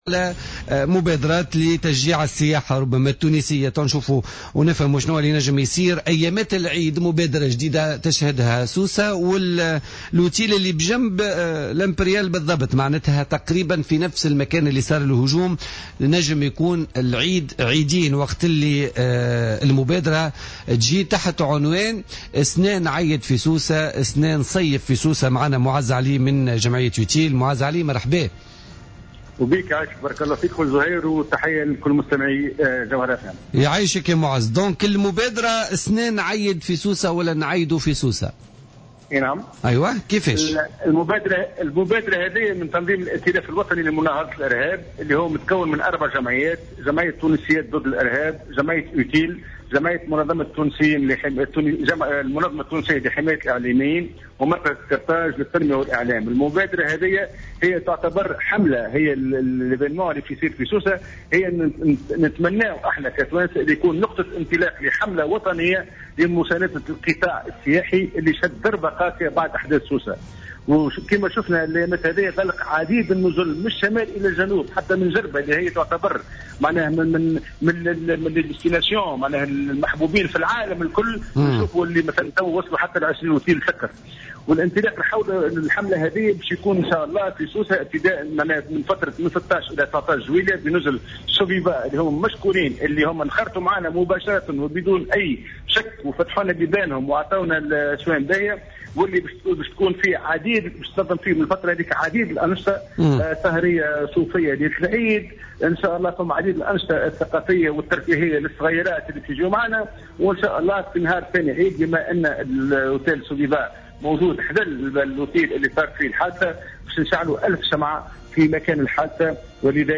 في مداخلة له في بوليتيكا